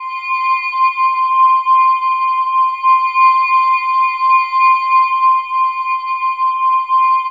Index of /90_sSampleCDs/Chillout (ambient1&2)/11 Glass Atmos (pad)